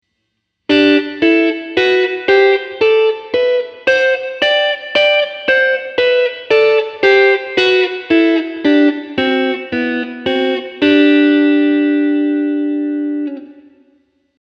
次は4度ダブルストップのパターンです。
4度音程のダブルストップ1弦、2弦